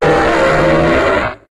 Cri de Brouhabam dans Pokémon HOME.